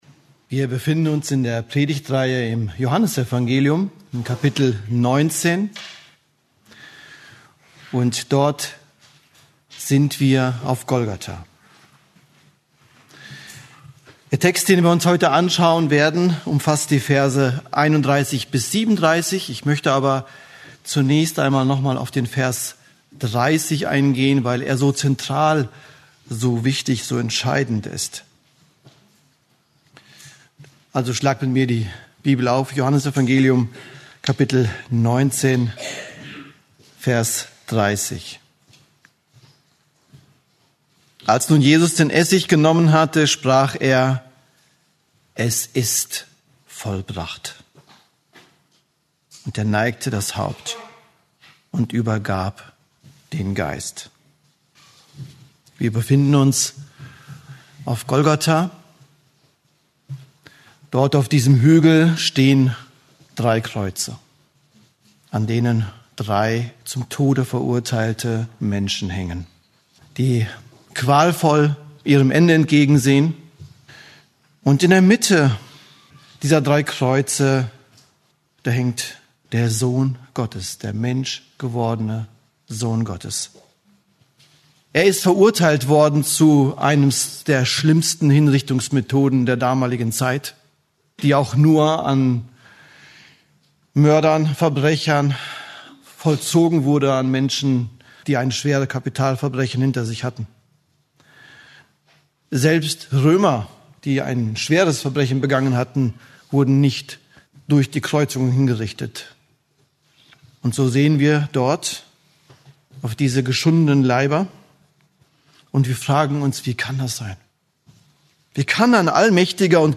Eine predigt aus der serie "Johannes Evangelium."